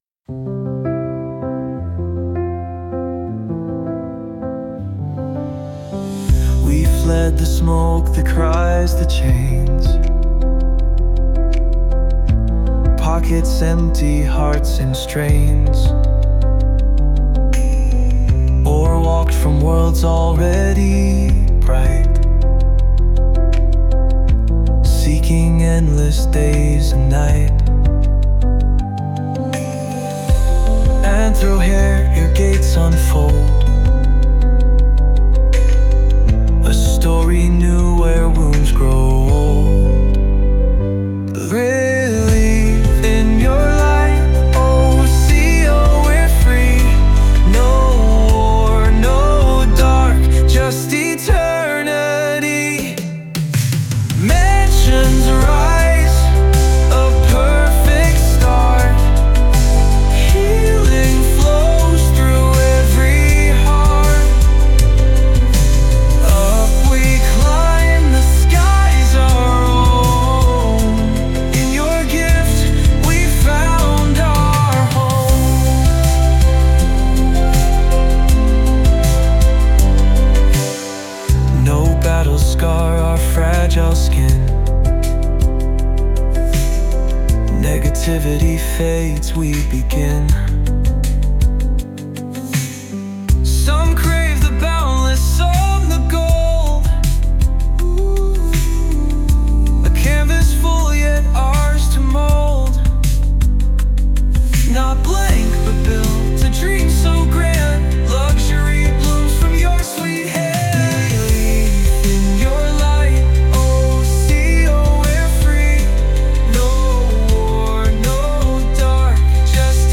Uplifting Folk Pop with Ambient Flourishes